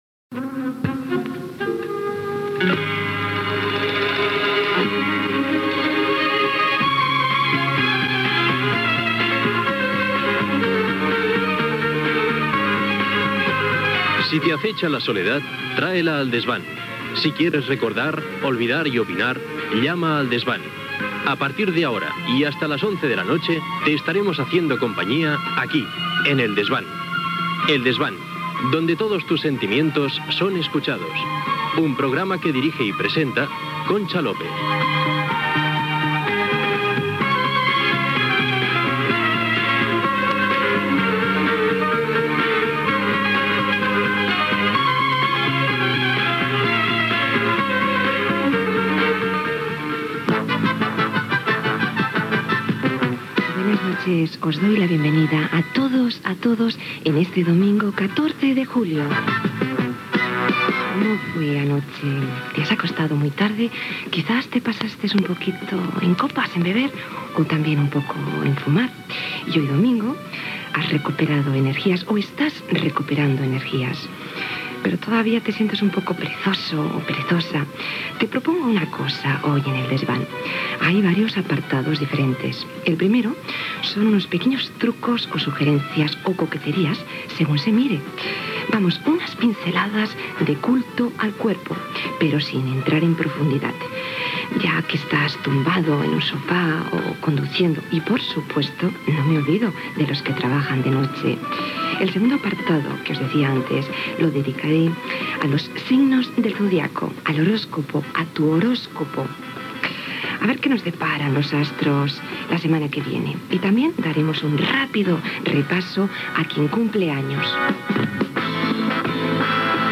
Careta del programa, presentació i sumari de continguts, hora, identificació de l'emissora, tema musical
Entreteniment